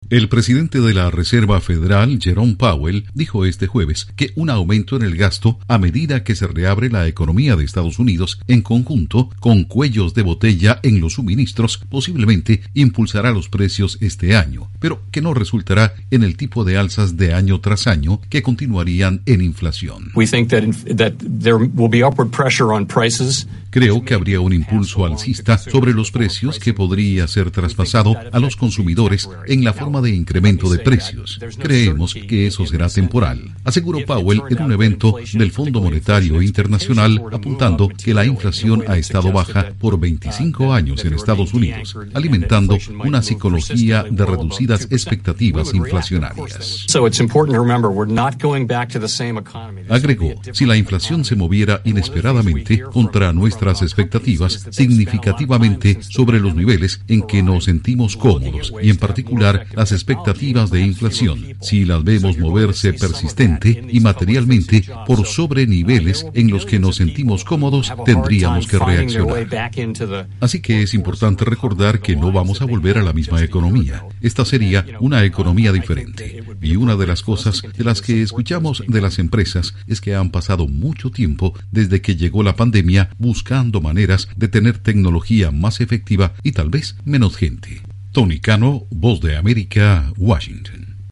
La Reserva Federal de Estados Unidos anticipa un aumento de precios este año, pero no inflación. Informa desde la Voz de América en Washington